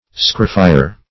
Search Result for " scarefire" : The Collaborative International Dictionary of English v.0.48: Scarefire \Scare"fire`\, n. 1.